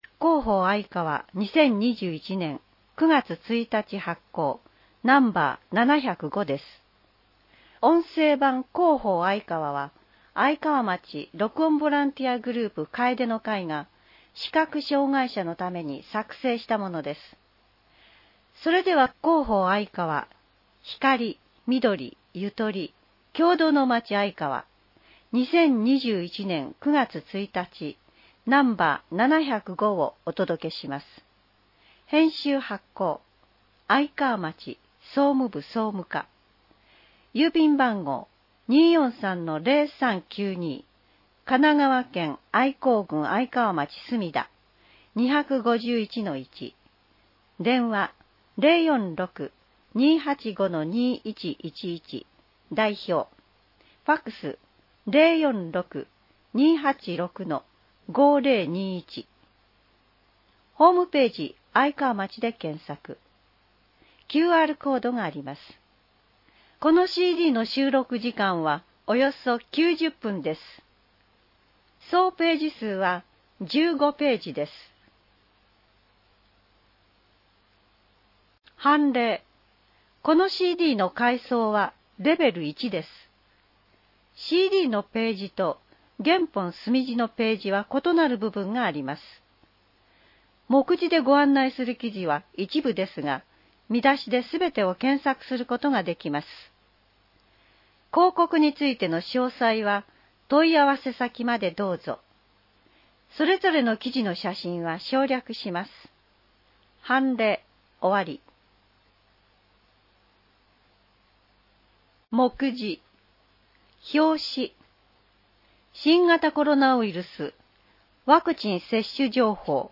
町の助成制度 (PDFファイル: 661.2KB) トピックス (PDFファイル: 796.1KB) 町政情報館 (PDFファイル: 866.5KB) インフォメーション (PDFファイル: 2.8MB) あいかわカレンダー (PDFファイル: 649.7KB) 音声版「広報あいかわ」 音声版「広報あいかわ」は、「愛川町録音ボランティアグループ かえでの会」の皆さんが、視覚障がい者の方々のために「広報あいかわ」を録音したものです。